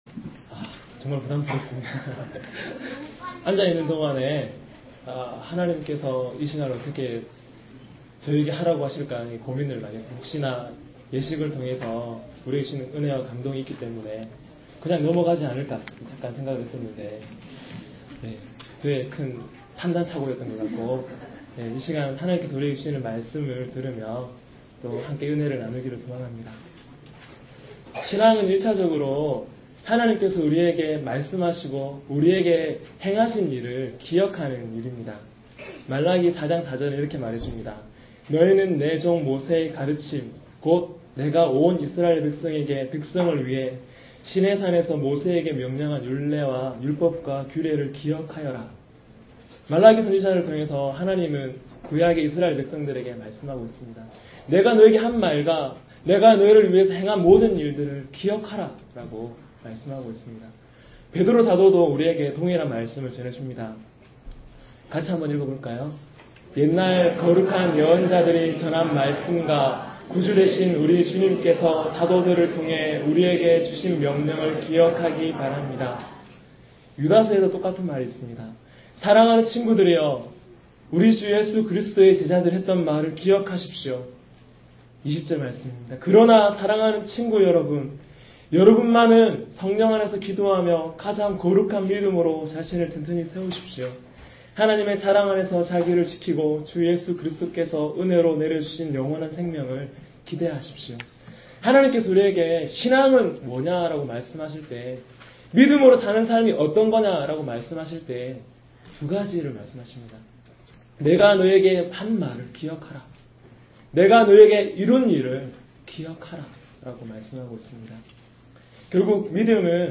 09년 05월 31일 주일 설교 "말씀의 사람, 믿음의 사람" (마14:22-34)